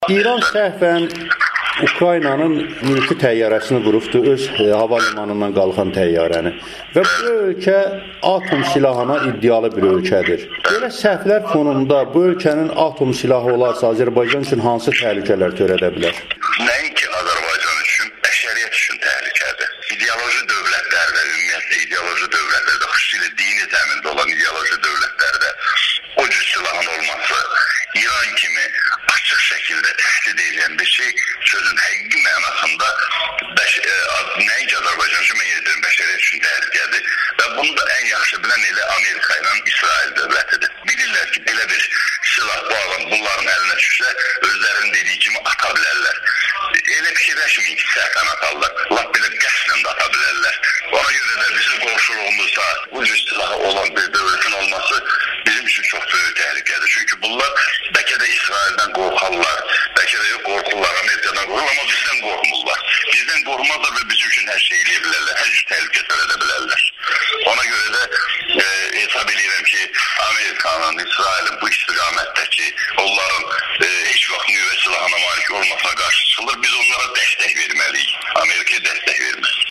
Amerikanın Səsinə müsahibəsində